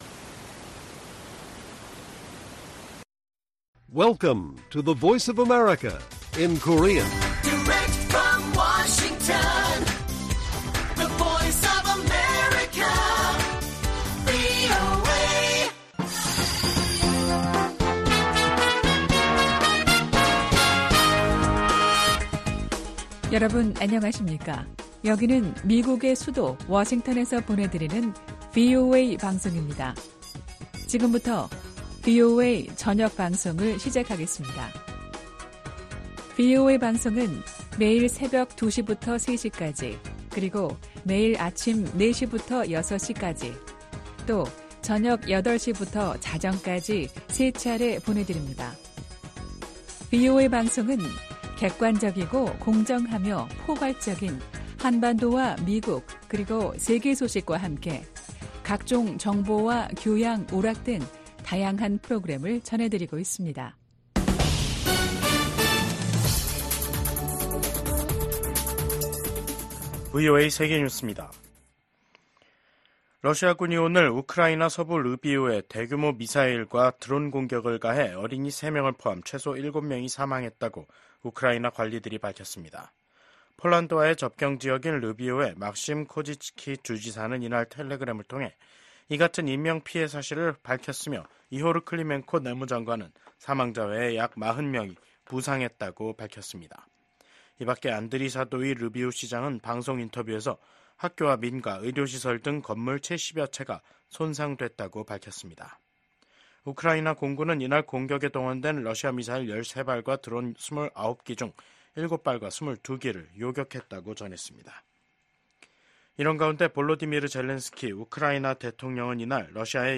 VOA 한국어 간판 뉴스 프로그램 '뉴스 투데이', 2024년 9월 4일 1부 방송입니다. 미국 국무부가 한국 정부의 대북 라디오 방송 지원 방침을 지지한다는 입장을 밝혔습니다. 기시다 후미오 일본 총리가 퇴임을 앞두고 한국을 방문해 윤석열 대통령과 회담을 합니다. 북한 열병식 훈련장 인근에 버스로 추정되는 차량 수십 대가 집결했습니다.